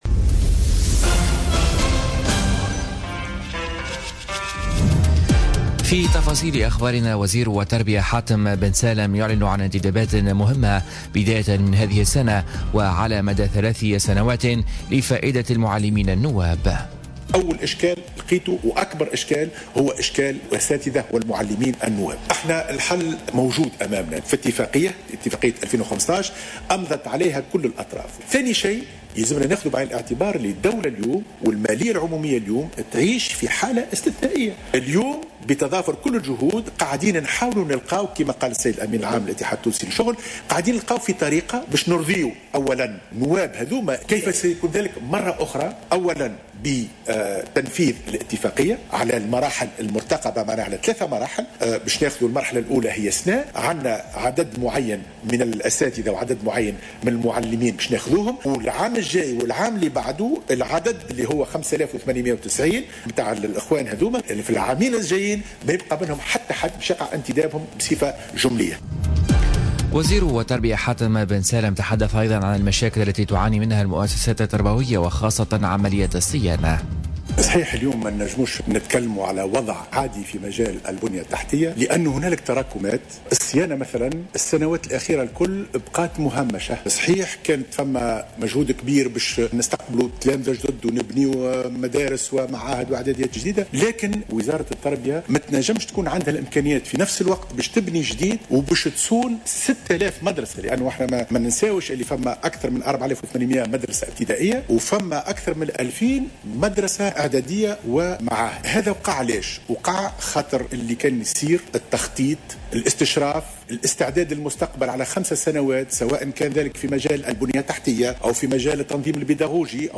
نشرة أخبار السابعة صباحا ليوم السبت 16 سبتمبر 2017